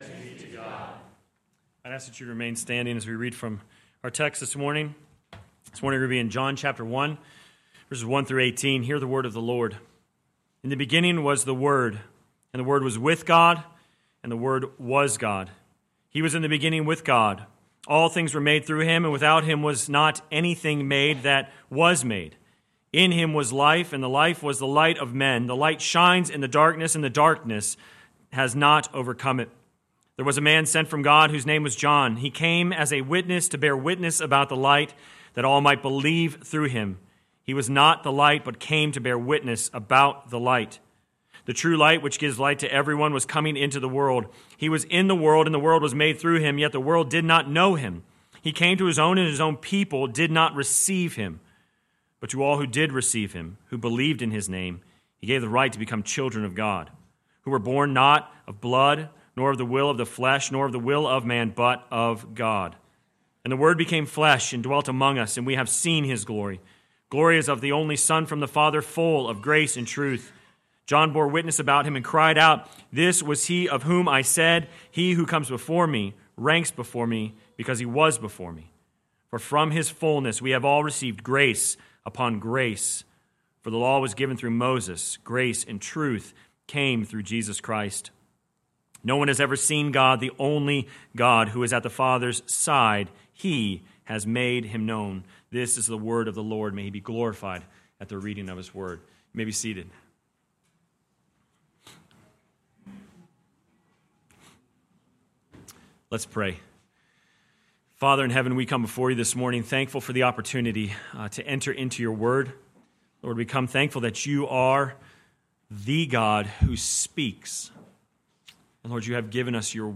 Sermon Text: John 1:1-18 First Reading: Genesis 1:1-5 Second Reading: Matthew 1:18-25